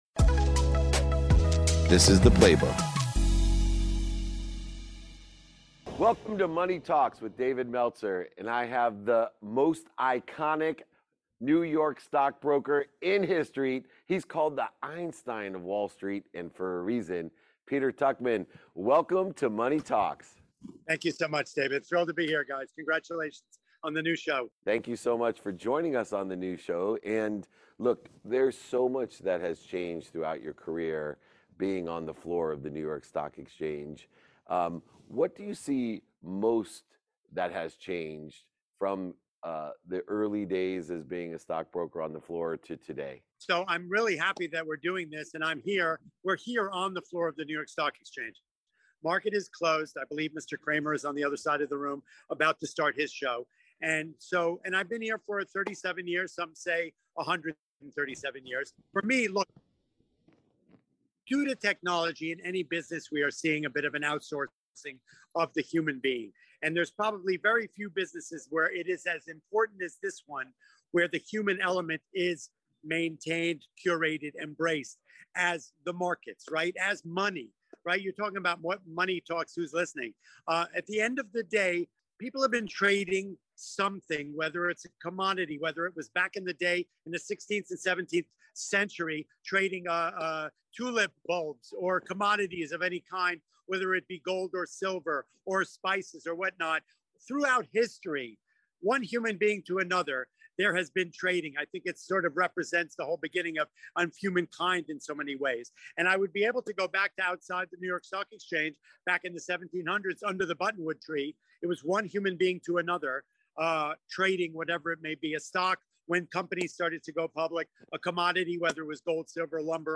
Stocks, Tech, and Human Connections | Conversation with Peter Tuchman